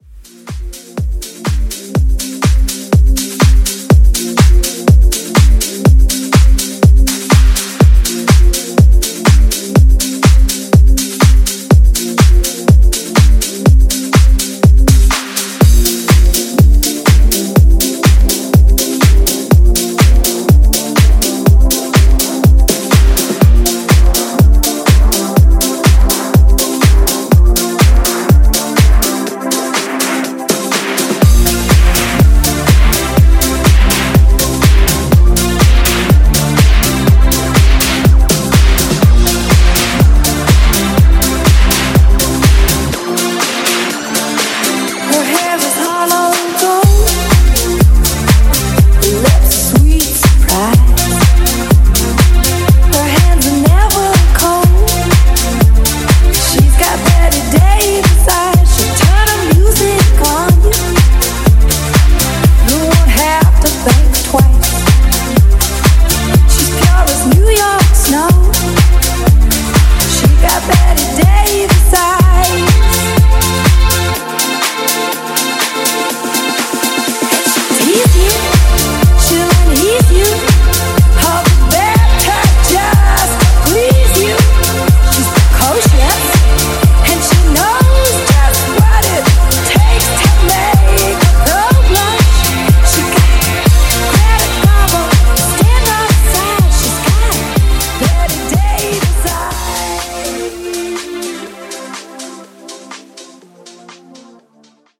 Genres: 70's , R & B
Clean BPM: 120 Time